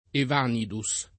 evanire v.; evanisco [evan&Sko], -sci — raro latinismo lett. per «svanire» — usato da filologi e archeologi il part. pass. e agg. evanito [evan&to], detto di scritture, inchiostri, colori, odori — come agg., e solo come agg., anche e meglio il più lett. evanido [ev#nido], conforme al lat. evanidus [